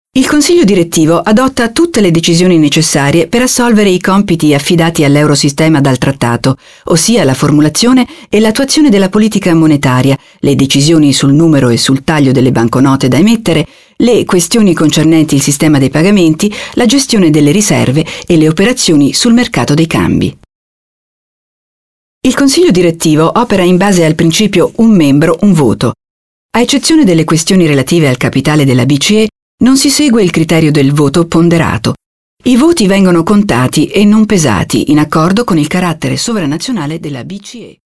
Europäische Zentralbank: Präsentationsfilm (italienisch)
Europäische Zentralbank - Präsentation.mp3